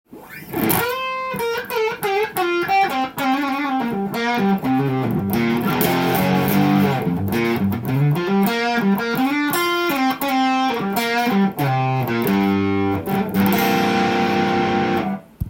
フロントピックアップで弾きましたが
納屋からたまたま見つかったギター。のような音がします。
歪ませて弾くと更に枯れている音がします！